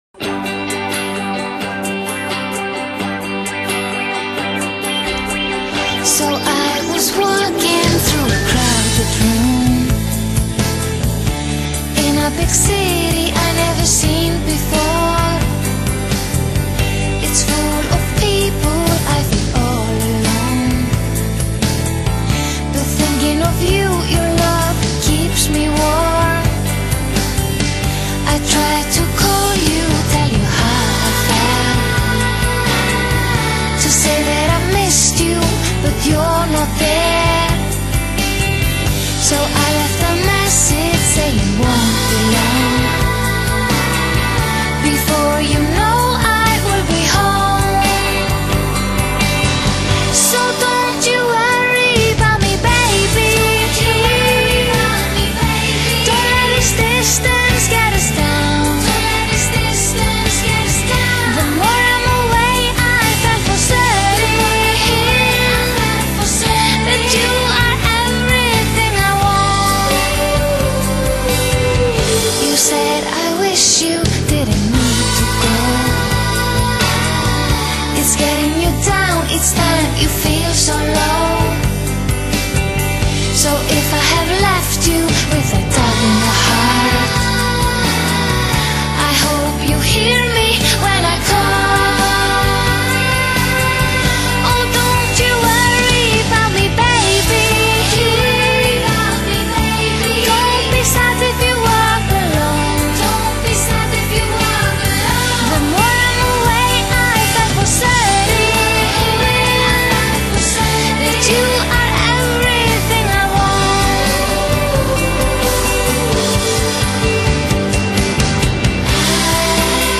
Brit Pop 旋律轻快，隐隐透出法国情调的作品。